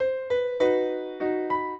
piano
minuet8-9.wav